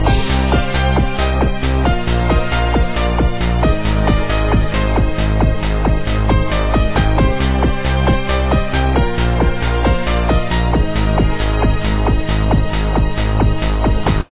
《天上的街市》课文朗读